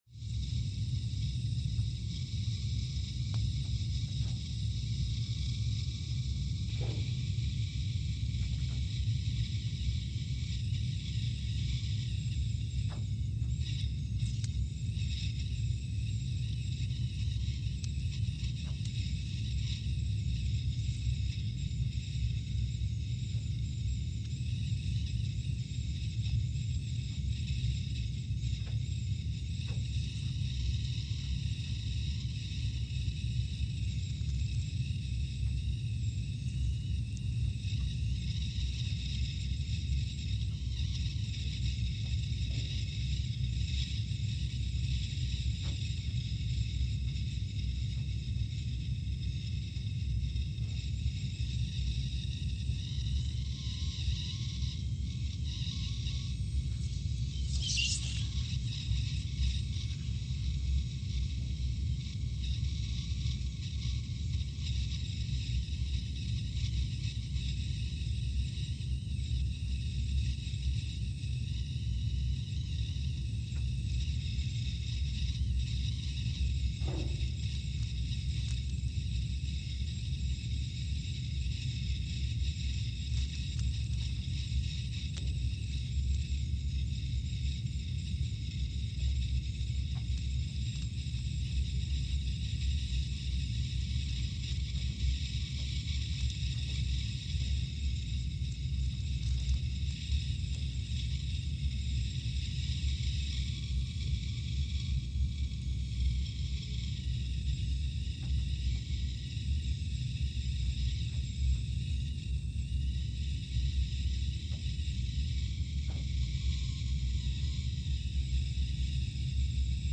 Scott Base, Antarctica (seismic) archived on September 16, 2019
Sensor : CMG3-T
Speedup : ×500 (transposed up about 9 octaves)
Loop duration (audio) : 05:45 (stereo)